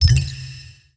SFX item_get_pickup.wav